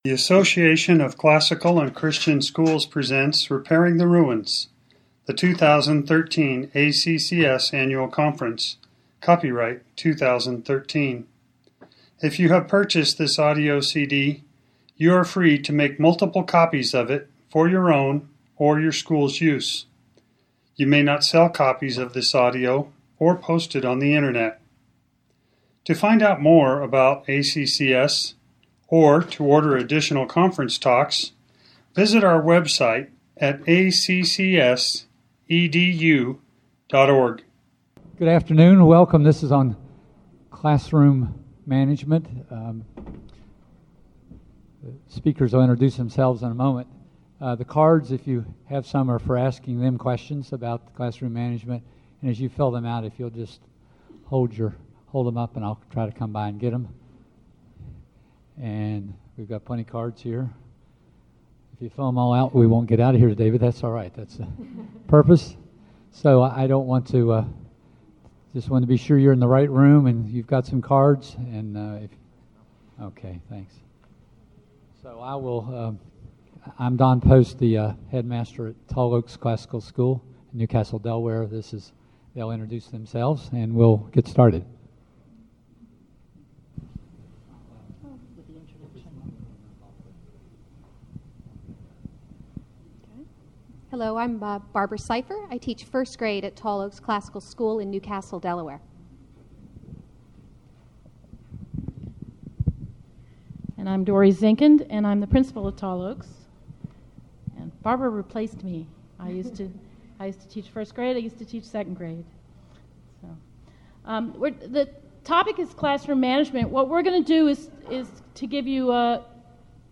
2013 Workshop Talk | 0:59:34 | K-6, General Classroom, Virtue, Character, Discipline